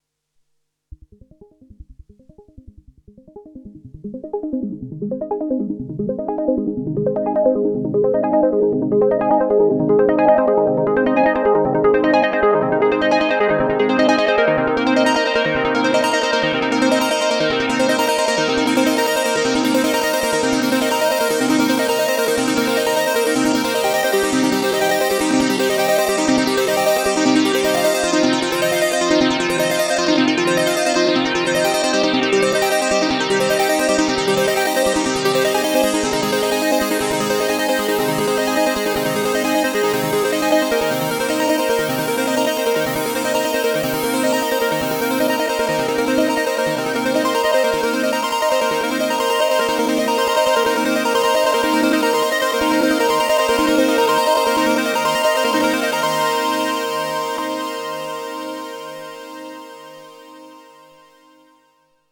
Roland Juno-6 Polyphonic Analog Synthesizer | smem